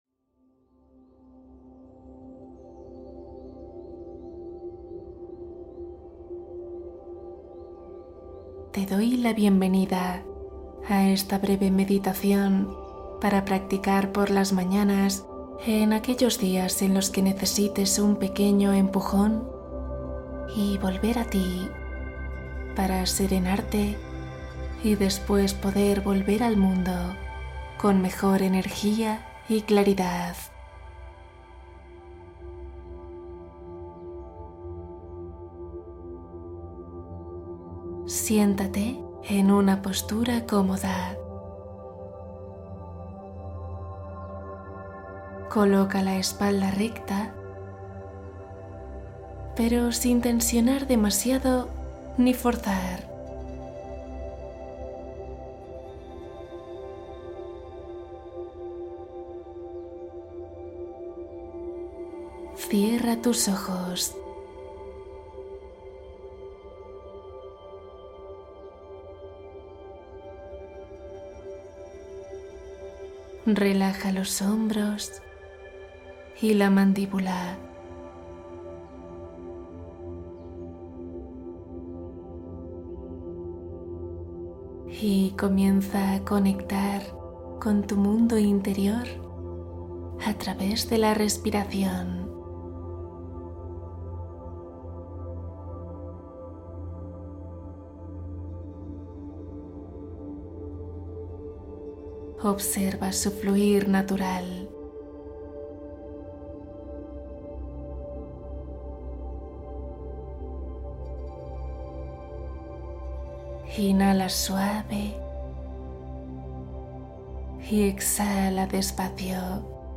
Transformar la noche con una narración de sabiduría suave